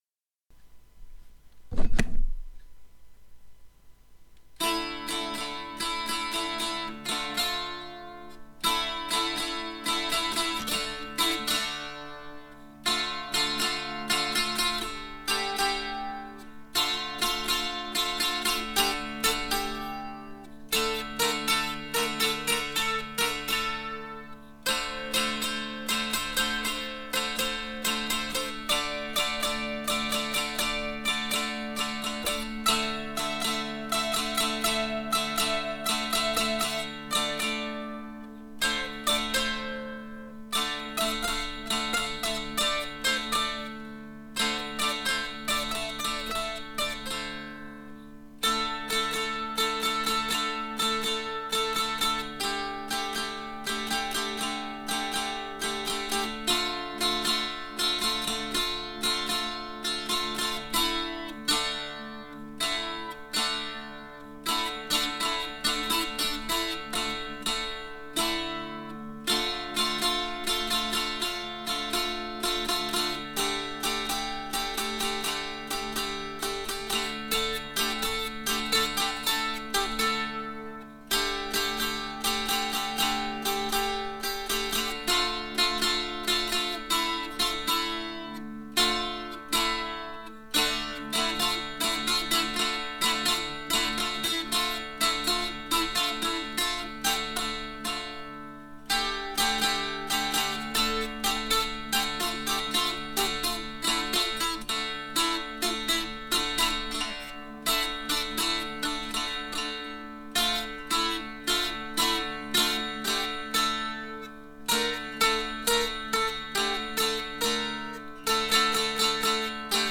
Außerdem hab ich wieder auf meinem Dulcimer rumgeklimpert und MP3's davon gemacht.
Mein wildes Geklimper
"Aufwärmübungen" (gut zum Abreagieren und ja, ich stolpere mehrmals)